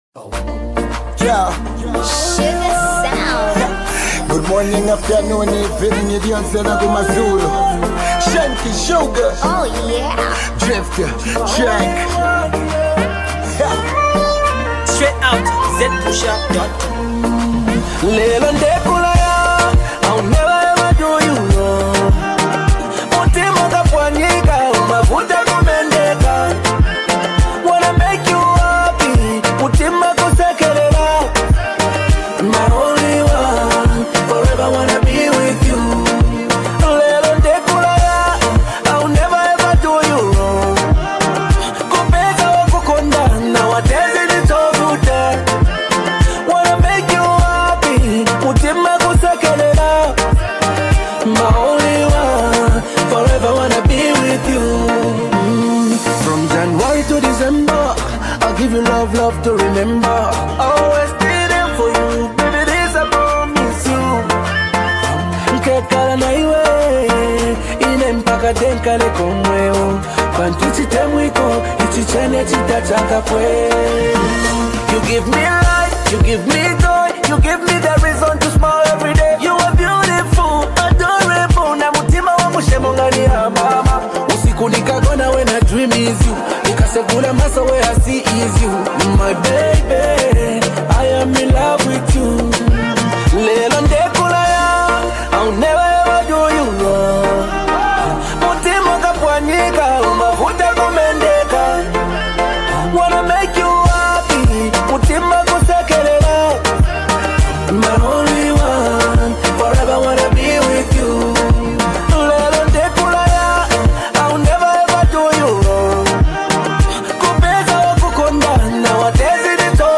Brand new love joint